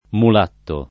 mulatto [ mul # tto ] s. m.